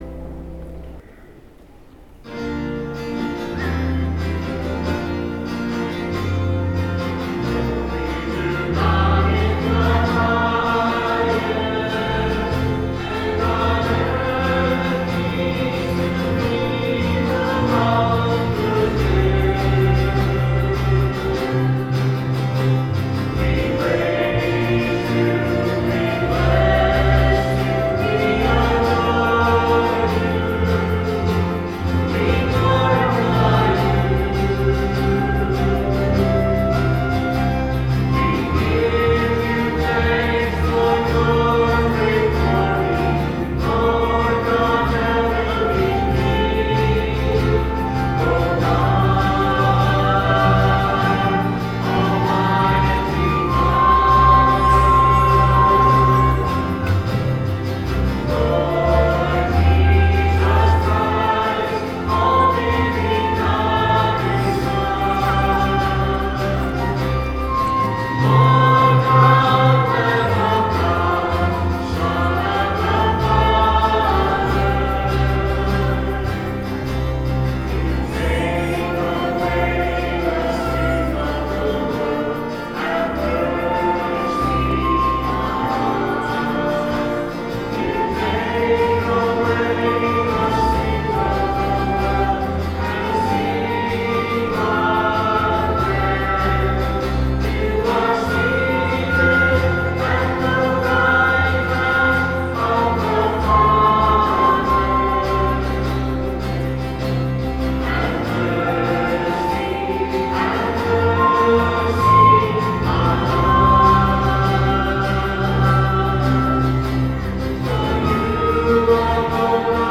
Music from the 10:30 Mass Easter Sunday, March 31, 2013: